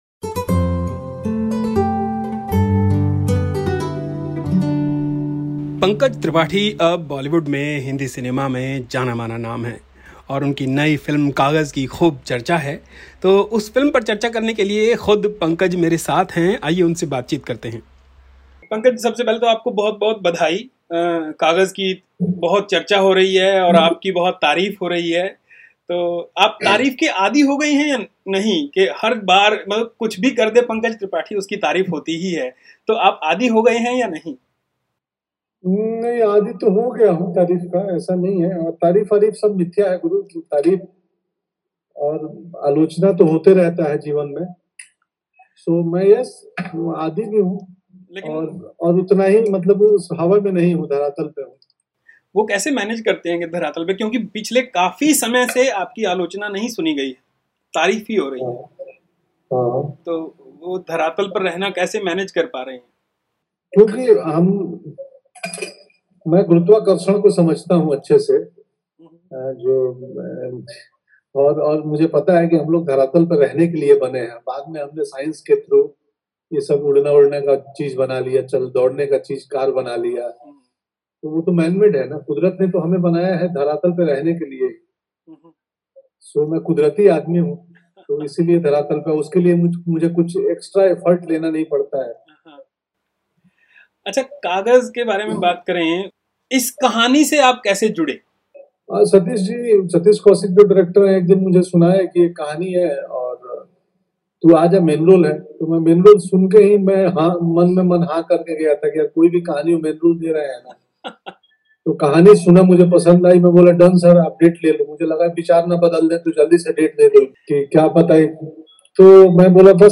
In conversation with the actor of 'Kaagaz', Pankaj Tripathi